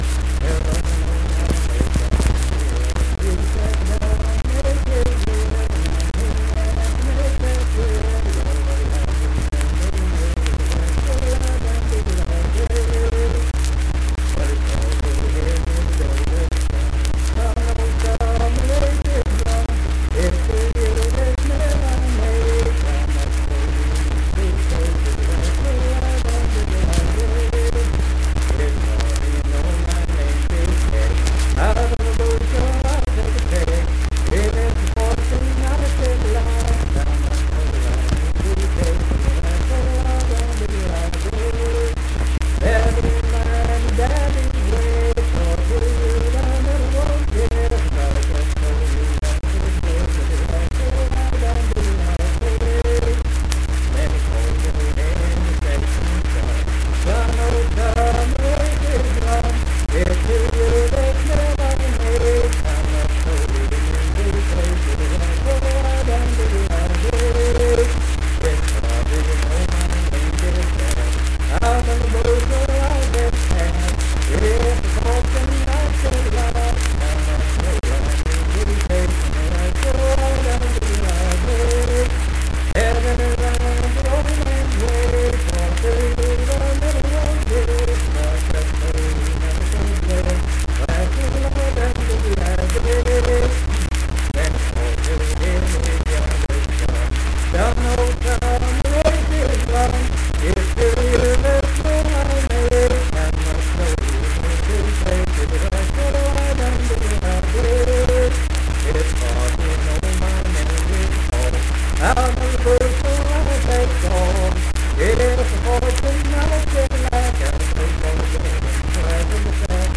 Unaccompanied vocal music
Performed in Kanawha Head, Upshur County, WV.
Voice (sung)